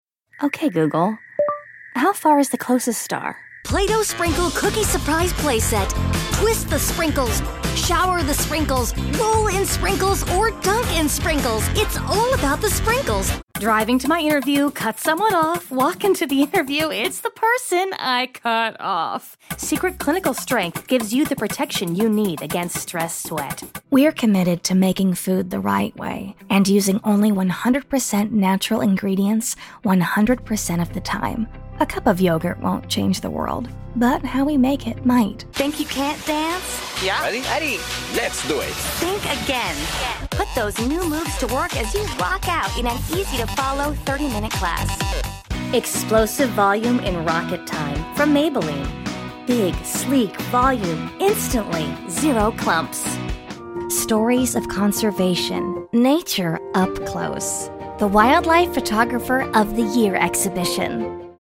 20 Something , 30 Something , Announcer , Articulate , Believable , Female , Versatile